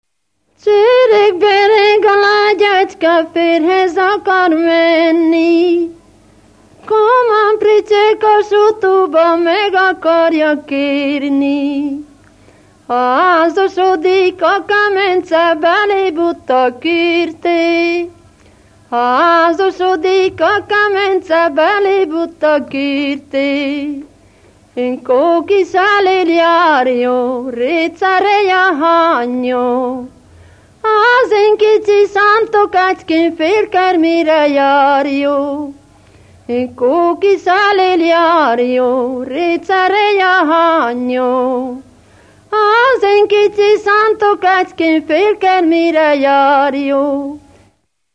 Moldva és Bukovina - Moldva - Lészped
Stílus: 7. Régies kisambitusú dallamok
Kadencia: b3 (1) b3 1